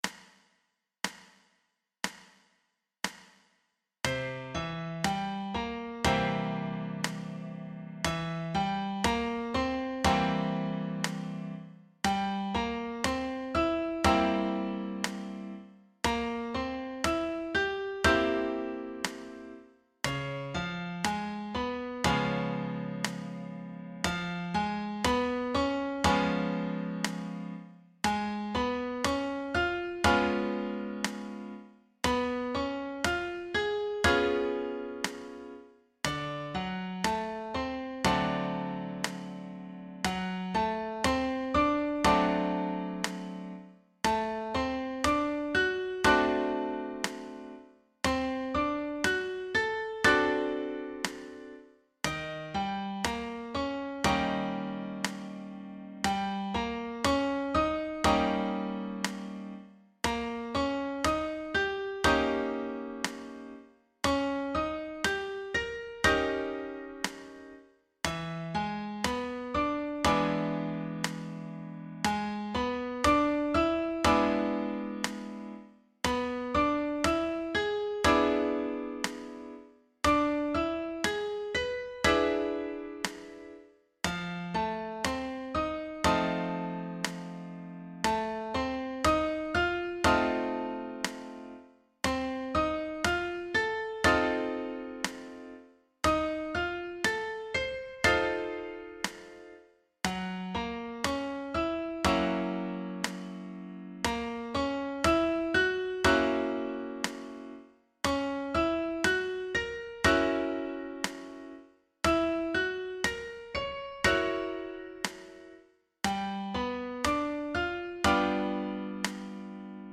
Versione per Pianoforte
L’esercizio seguente si basa sugli accordi di 7 dominante con i rispettivi rivolti, trattati sotto forma di arpeggio.